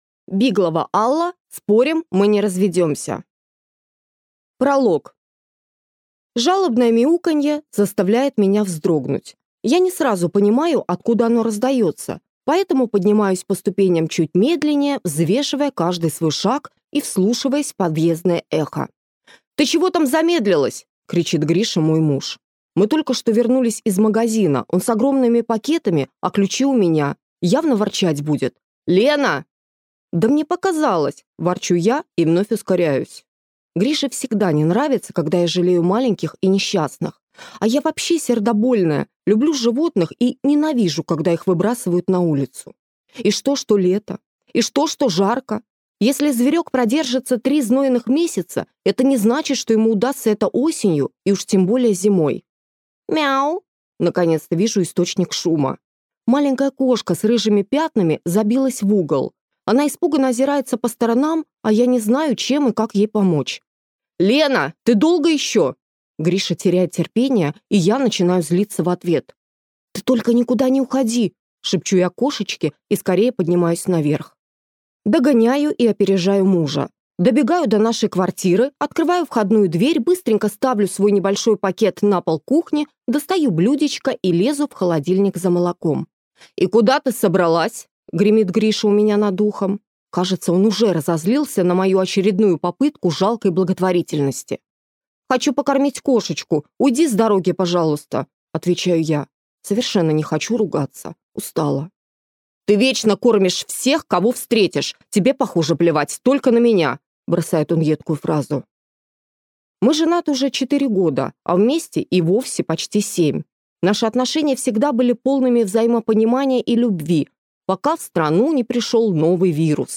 Аудиокнига Спорим, мы не разведёмся?